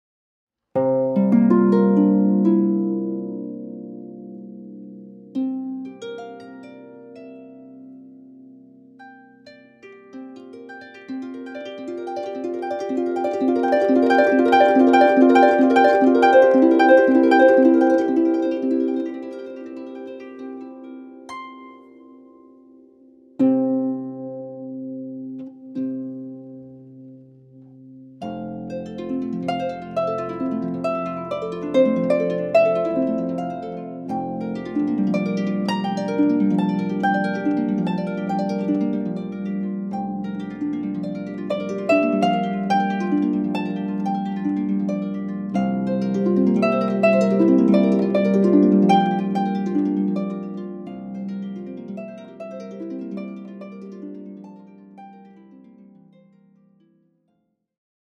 • Harfe